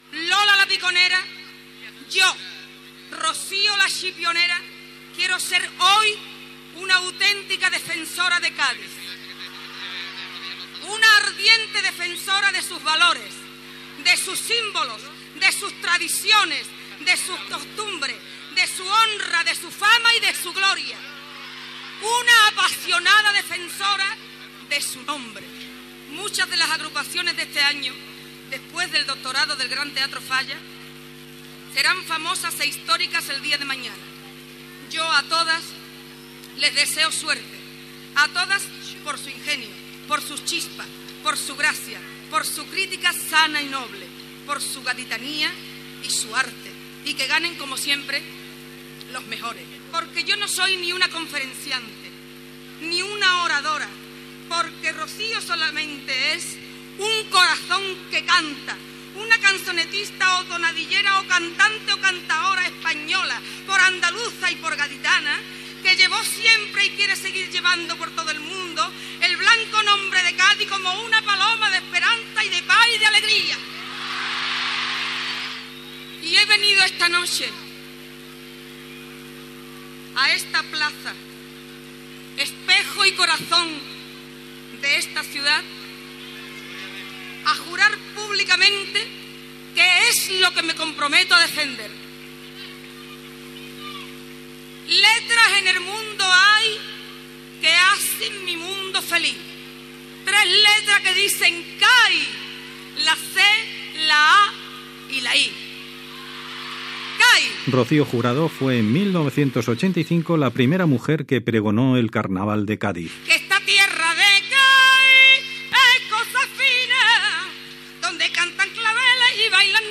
La cantant Rocío Jurado fa el pregó del "Carnaval de Cádiz"